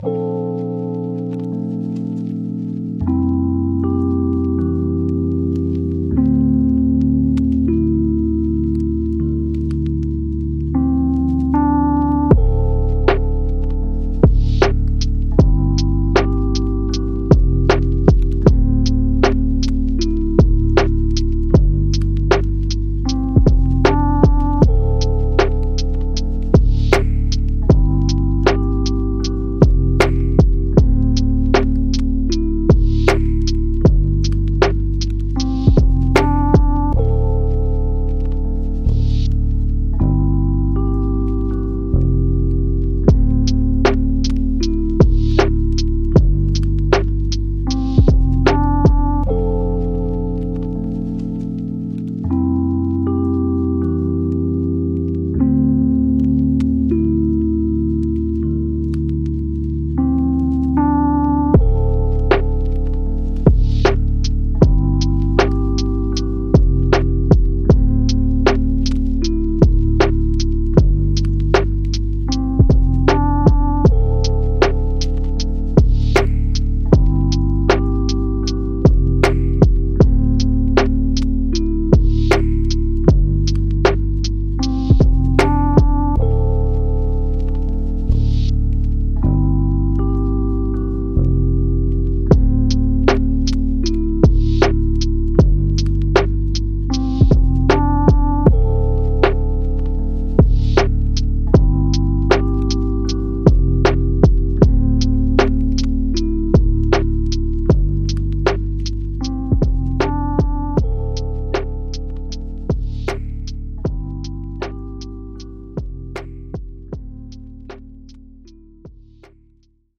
Track2_Soothing_Instrumental.mp3